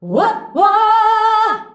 UAH-UAAH A.wav